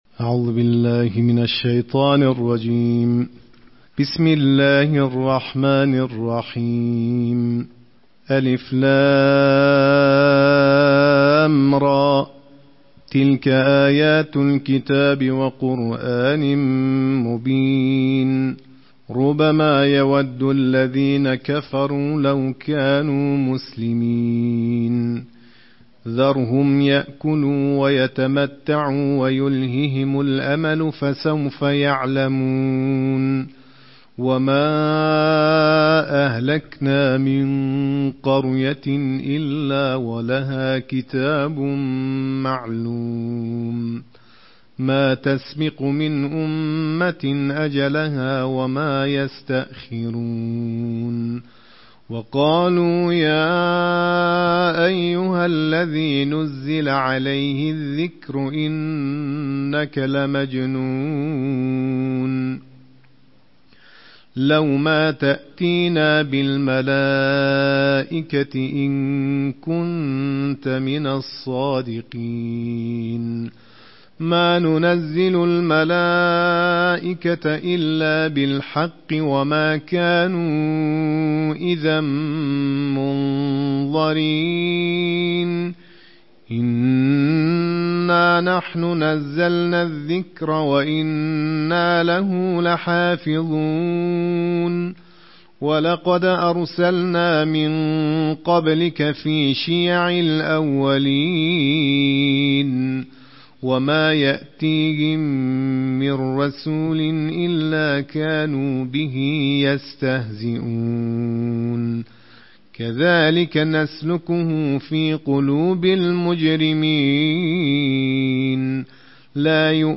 نړیوال قارِيان ،د قرآن کریم د څوارلسمې(۱۴) سپارې یا جزوې د ترتیل قرائت